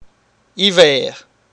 • ÄäntäminenCanada (Montréal):
• IPA: [i.væɛ̯ʁ̥]